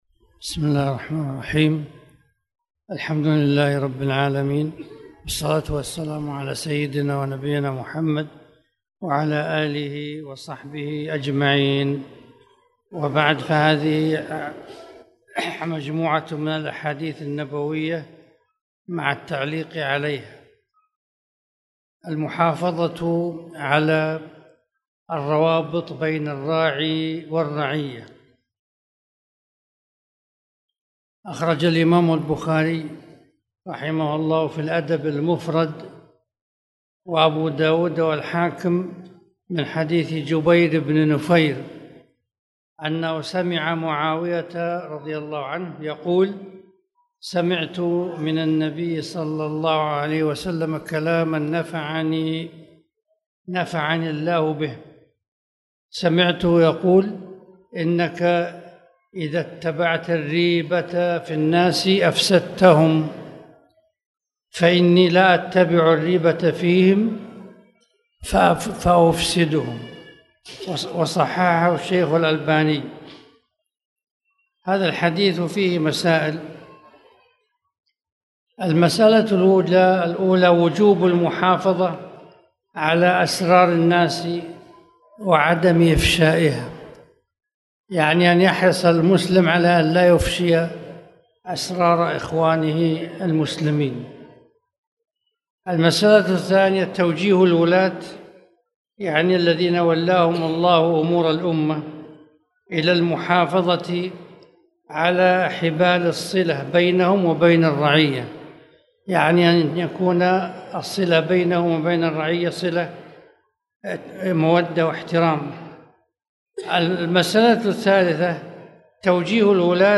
تاريخ النشر ٣٠ ربيع الثاني ١٤٣٨ هـ المكان: المسجد الحرام الشيخ